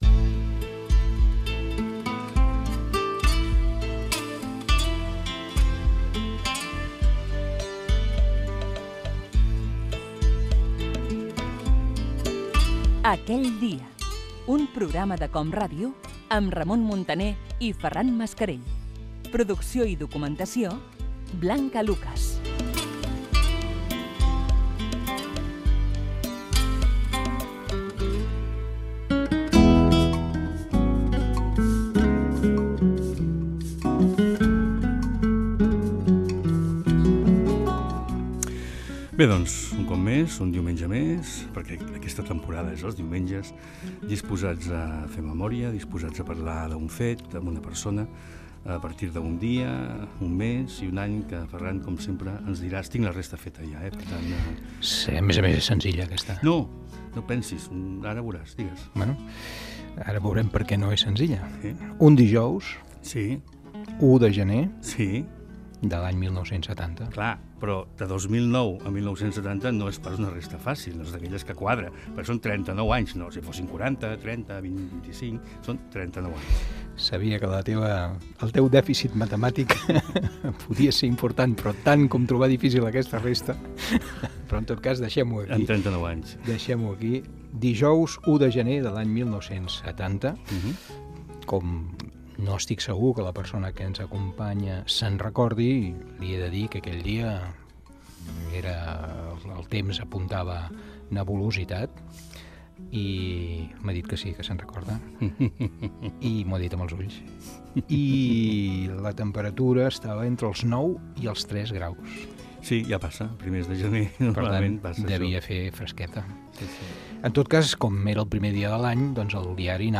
Careta del programa. Recorden l'1 de gener de 1970, quan Jordi Estadella va començar a Radio Juventud de Barcelona. Entrevista a Estadella sobre els seus records com oïdor i els seus inicis a la ràdio
FM